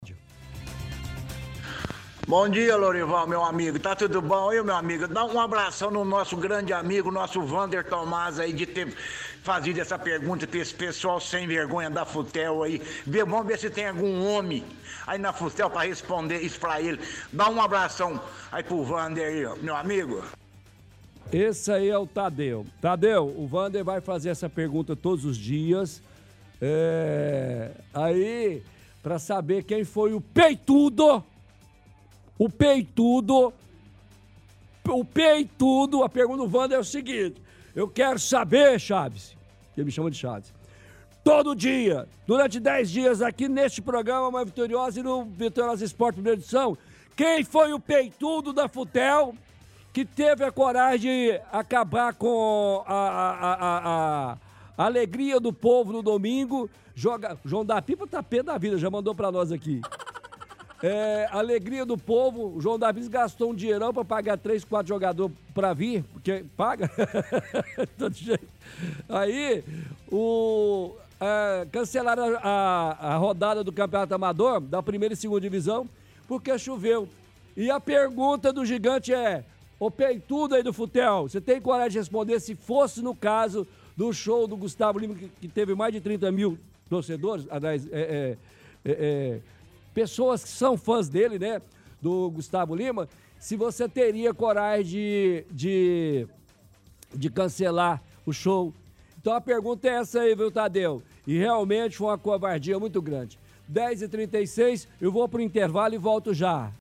Outro ouvinte também reclama de cancelamento dos jogos do amador